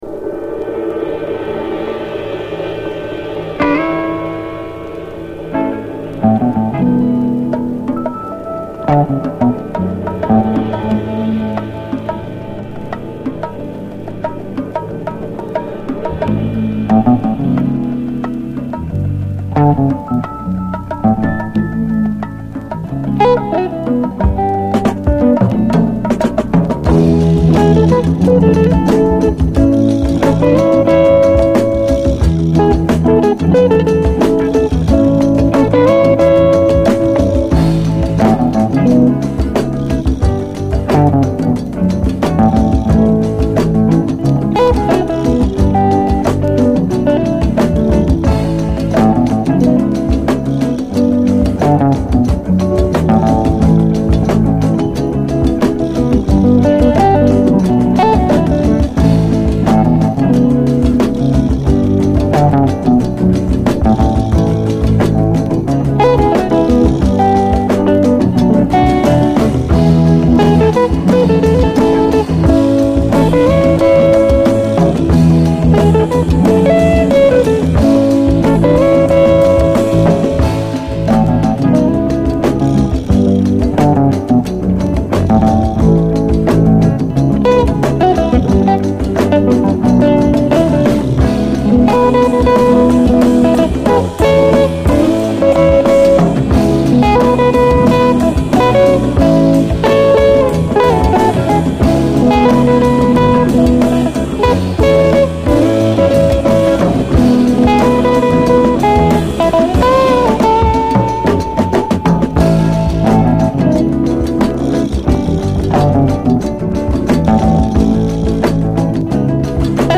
SOUL, 70's～ SOUL
泣きのギターに崩れ落ちる！
泣きのギターに崩れ落ちる、傑作メロウ・ファンク！